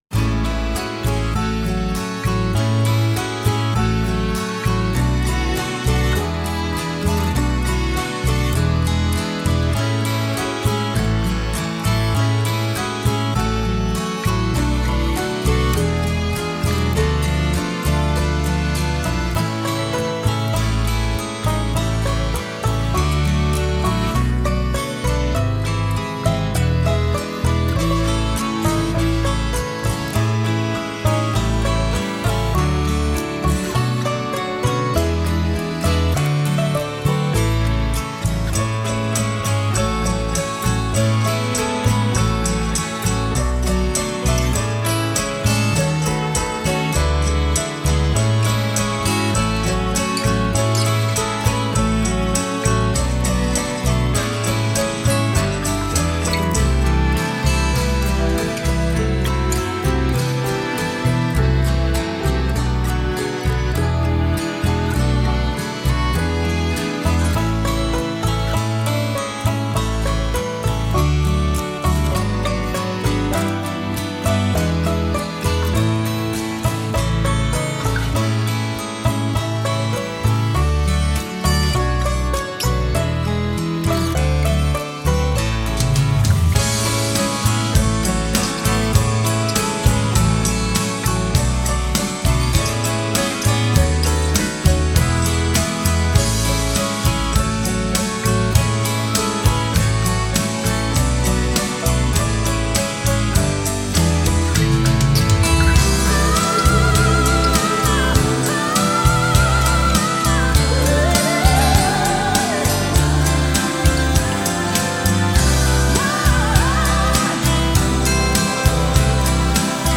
Genre: Pop-Folk.